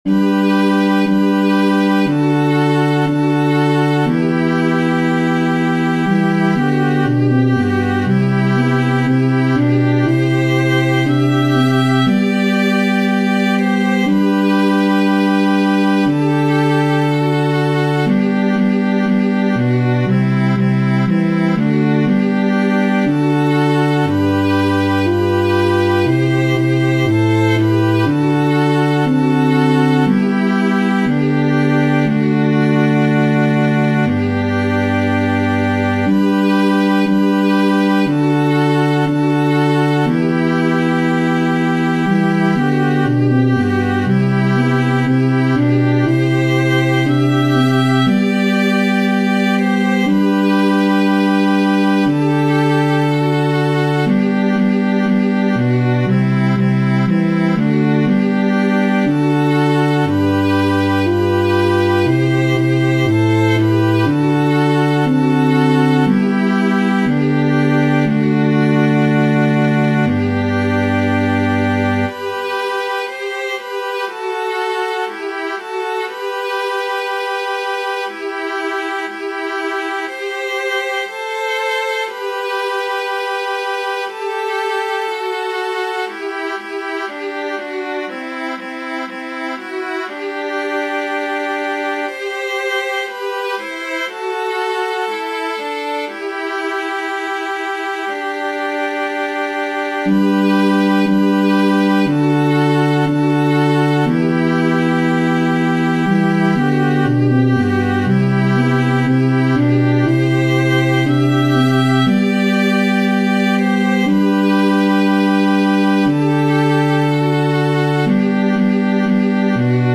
Antienne d'ouverture Téléchargé par